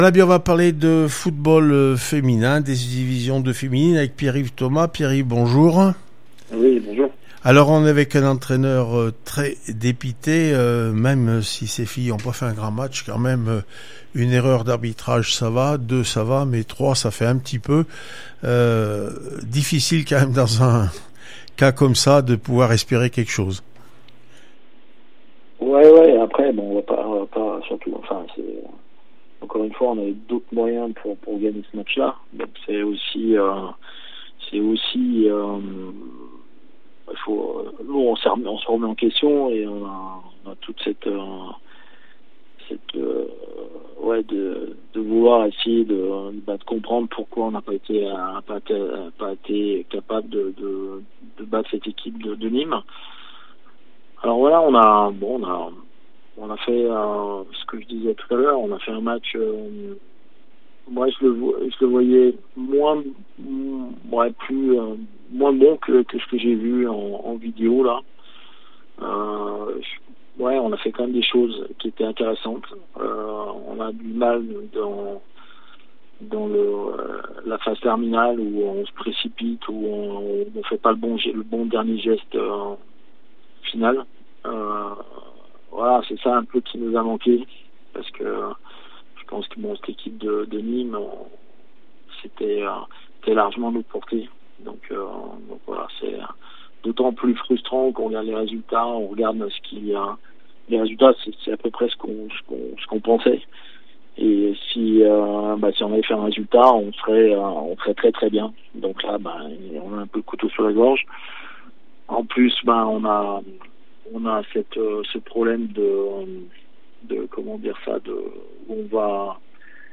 d2 féminine foot le Puy foot 43 0-2 Nîmes olympique réactions après match